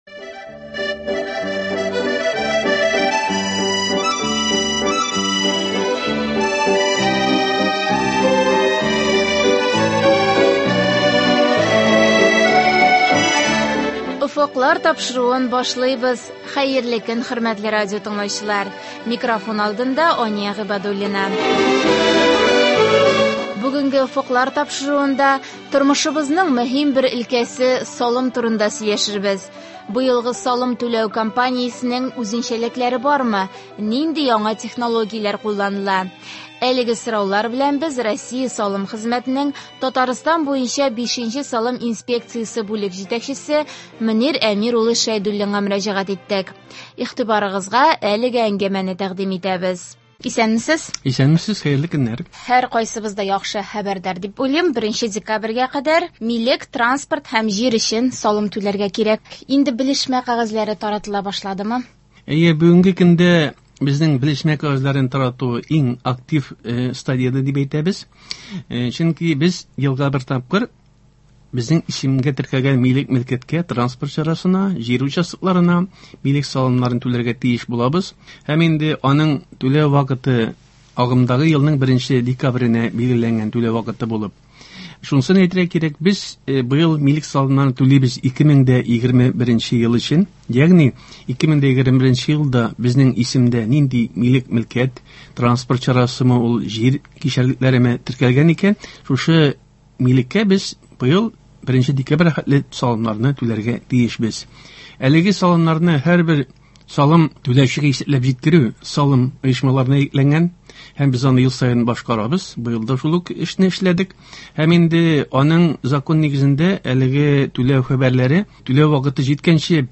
Игътибарыгызга әлеге әңгәмәне тәкъдим итәбез.